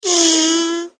Google Animal Sounds
An article from Indian Express mentions that "the sounds have been recorded from live animals" and the feature works for "the top searched for animals in Google and animals with some of the most iconic sounds".
turtle.mp3